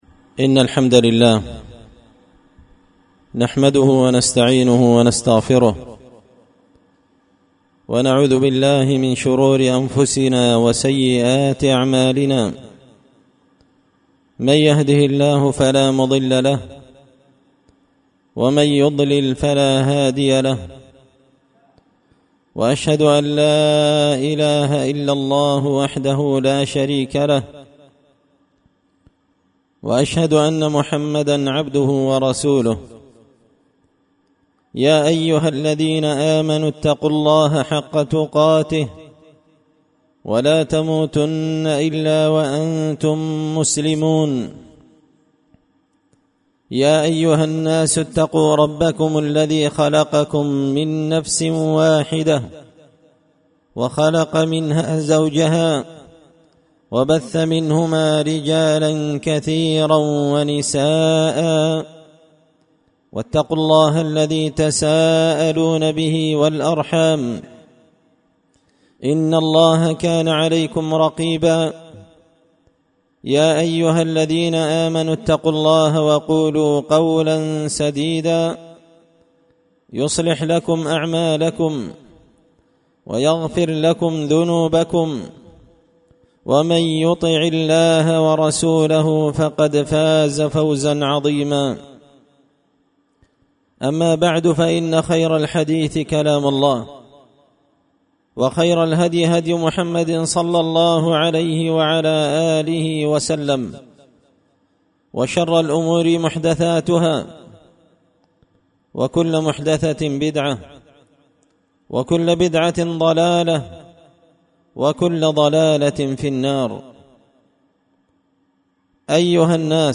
خطبة جمعة بعنوان – و لتنظر نفس ماقدمت لغد
دار الحديث بمسجد الفرقان ـ قشن ـ المهرة ـ اليمن